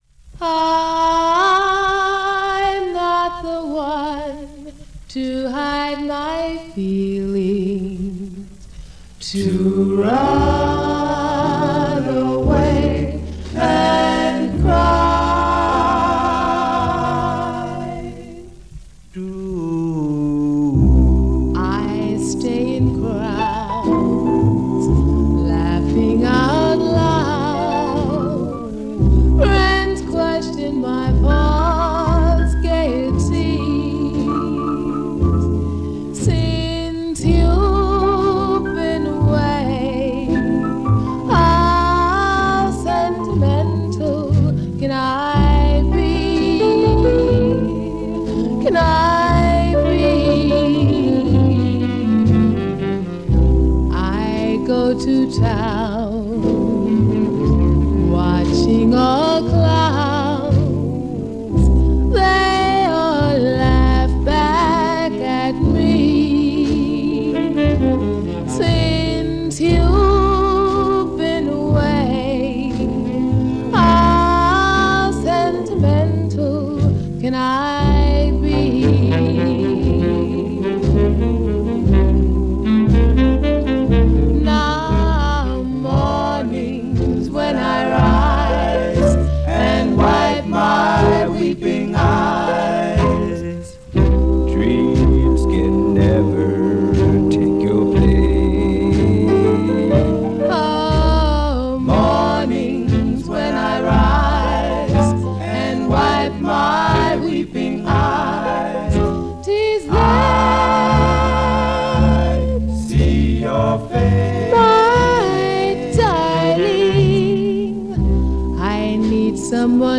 R&B Vocal group